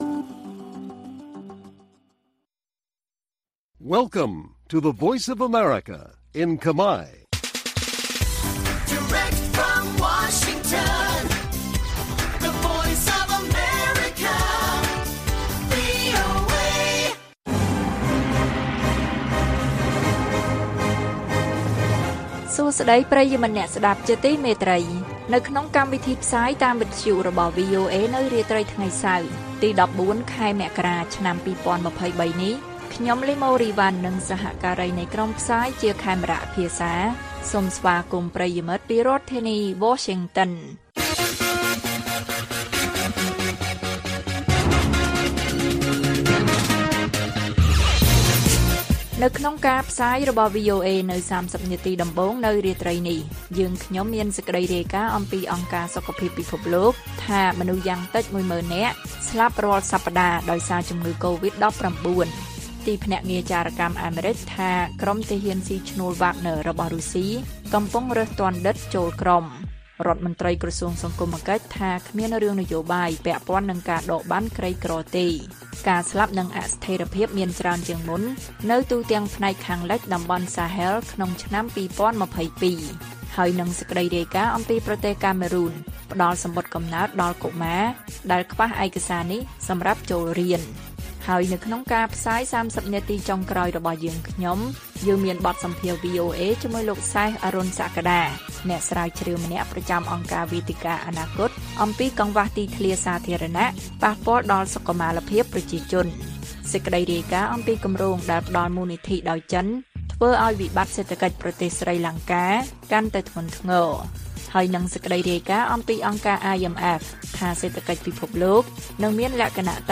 ព័ត៌មានពេលរាត្រី ១៤ មករា៖ បទសម្ភាសន៍ VOA៖ កង្វះទីធ្លាសាធារណៈប៉ះពាល់ដល់សុខុមាលភាពប្រជាជន